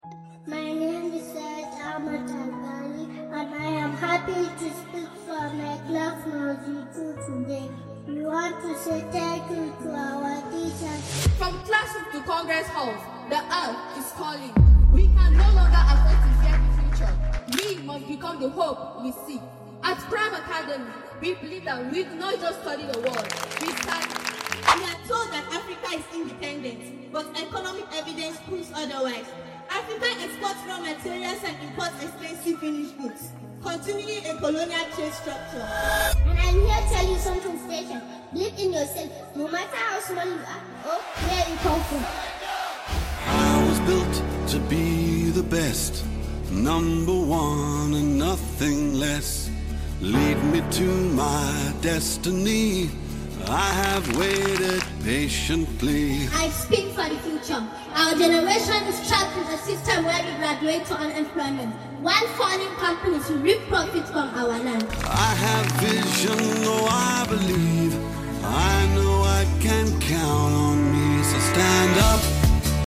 Smooth sound transition it is🎥🔥 sound effects free download